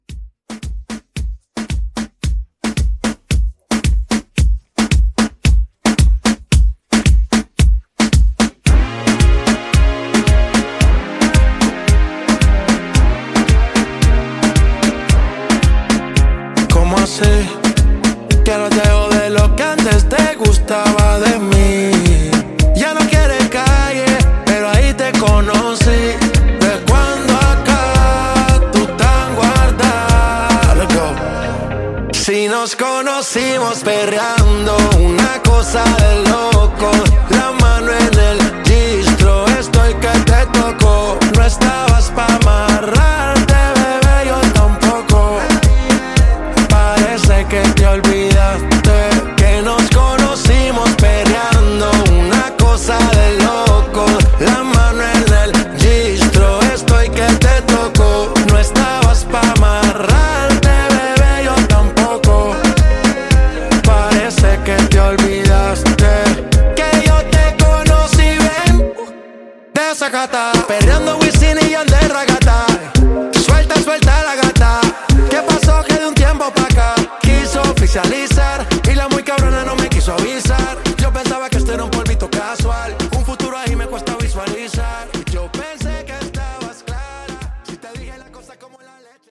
Genres: DANCE , LATIN Version: Clean BPM: 112 Time